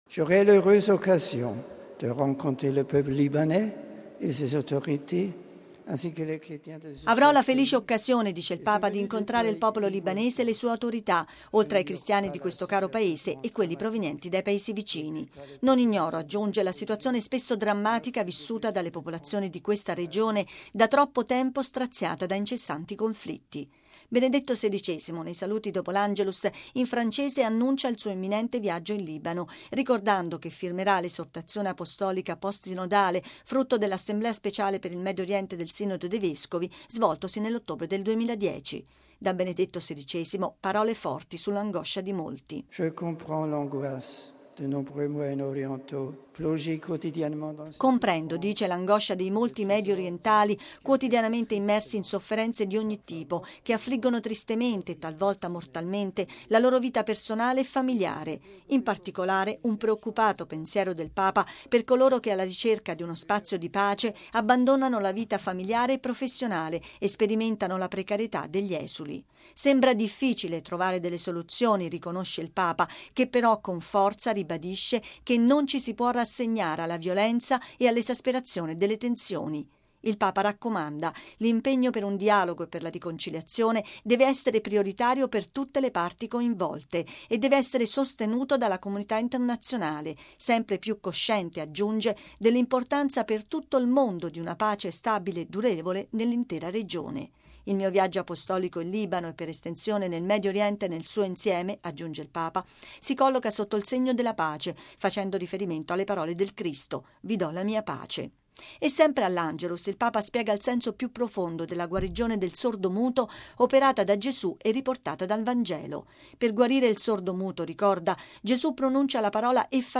Così Benedetto XVI nei saluti dopo l’Angelus, in francese, annuncia il suo imminente viaggio in Libano, ricordando che firmerà l’Esortazione apostolica post-sinodale, frutto dell’Assemblea speciale per il Medio Oriente del Sinodo dei Vescovi, svoltosi nell’ottobre del 2010.